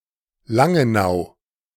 Langenau (German pronunciation: [ˈlaŋənaʊ]
De-Langenau.ogg.mp3